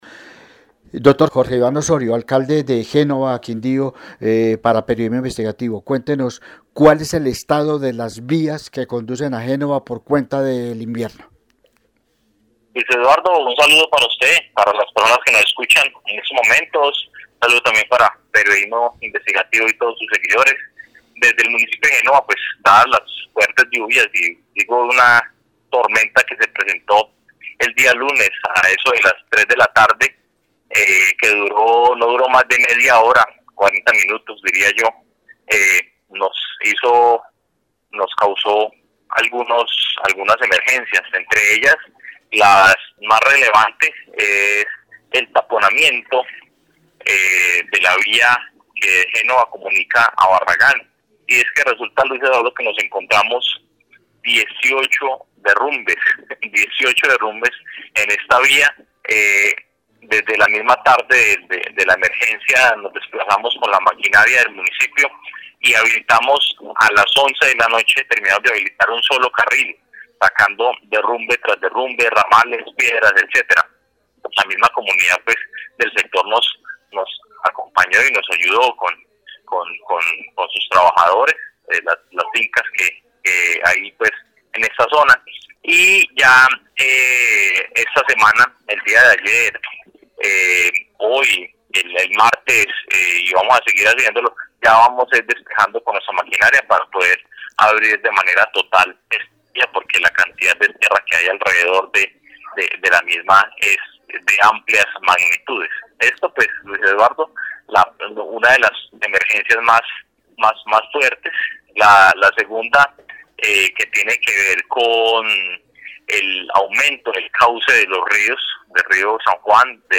Audio del alcalde de Génova Jorge Iván Osorio:
Jorge-Iván-Osorio-Alcalde-de-Genóva.mp3